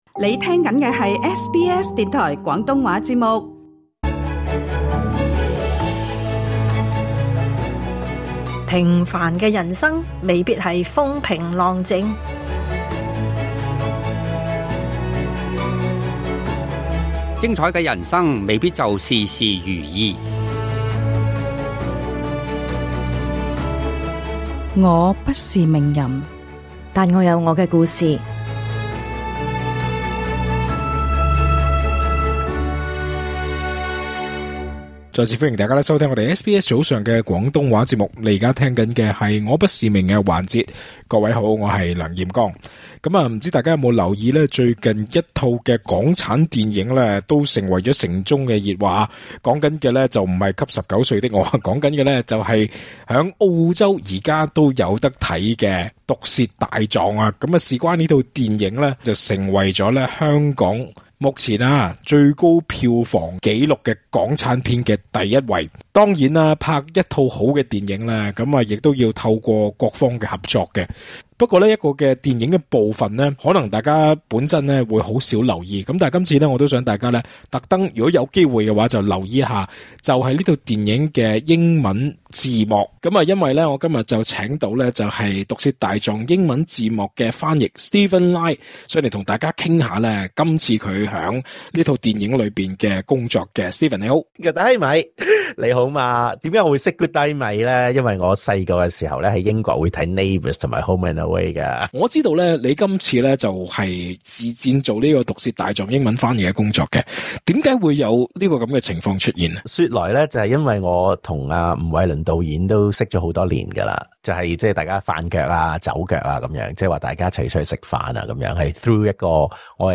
更多訪問內容，請聽足本錄音。